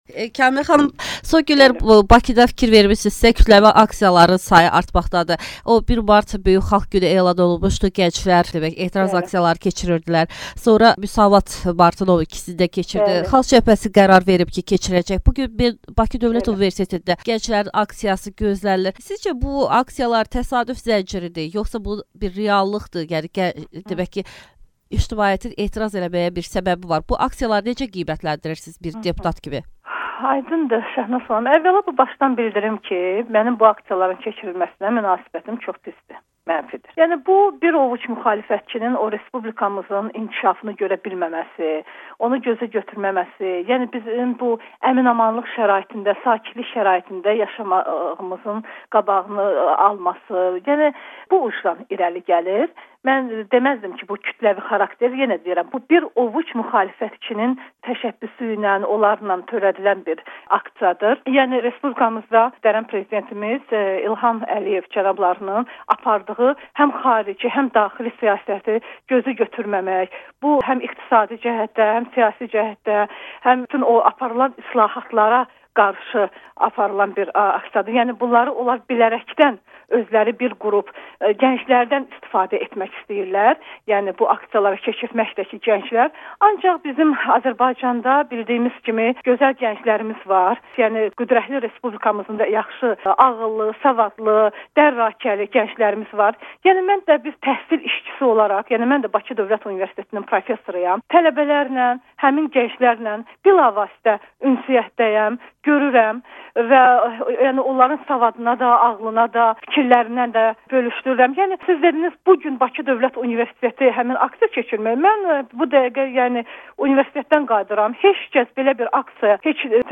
YAP üzvü, deputat Kamilə Əliyeva AzadlıqRadiosunun suallarını cavablandırır.